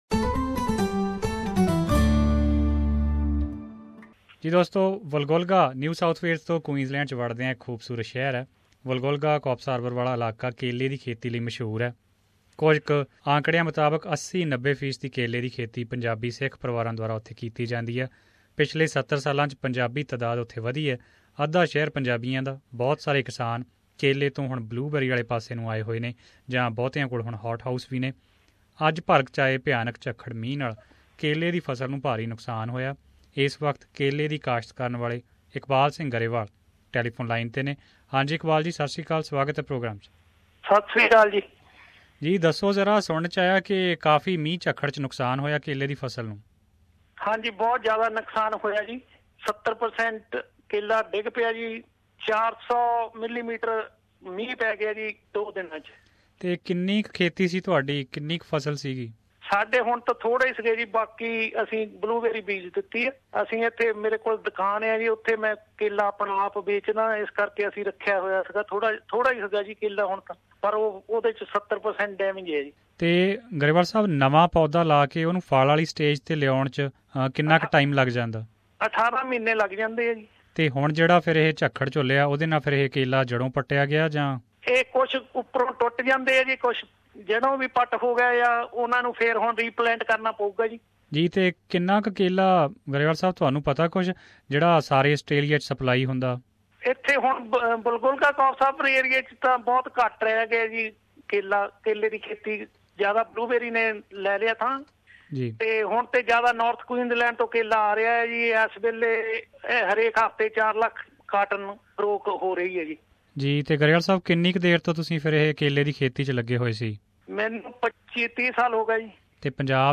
SBS Punjabi